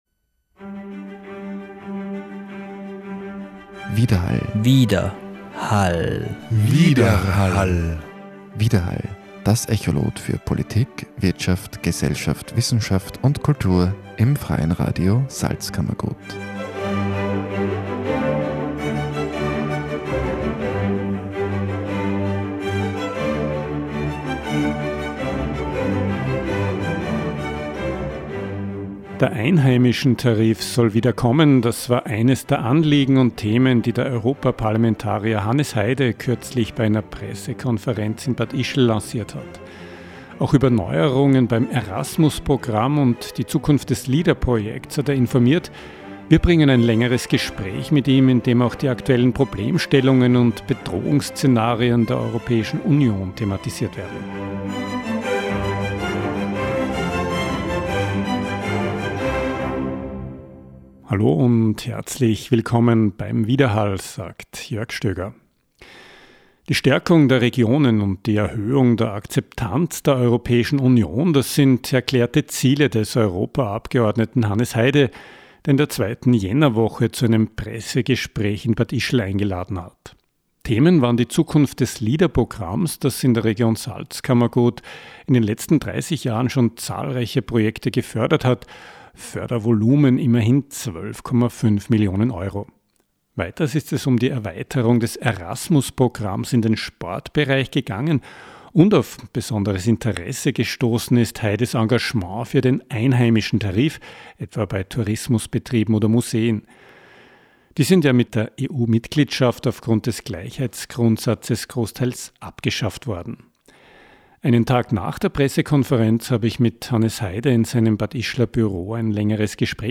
Gespräch mit Europaabgeordneten Hannes Heide